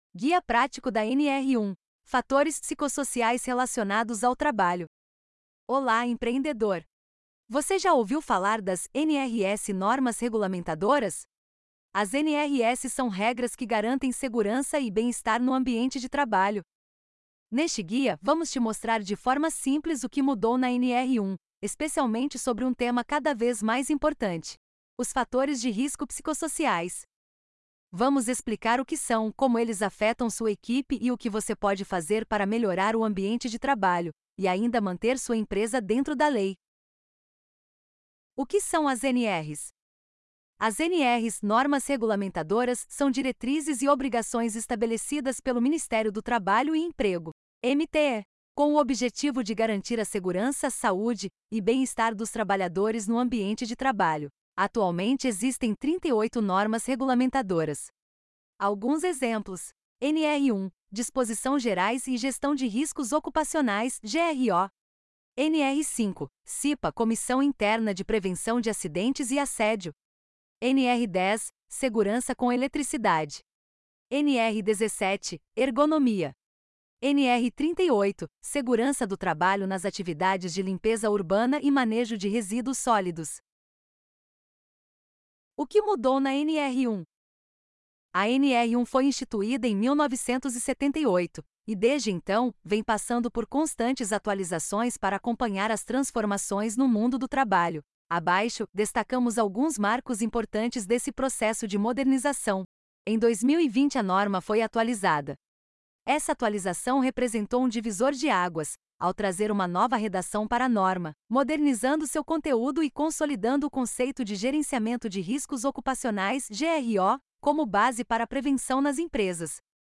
A nova atualização da NR 1 inclui oficialmente os fatores psicossociais como parte da gestão de riscos ocupacionais. Este audiobook explica de forma simples o que mudou, quais riscos precisam ser observados e como eles afetam diretamente o bem-estar e a produtividade das equipes. Com exemplos práticos, mostra soluções aplicáveis em empresas de todos os portes!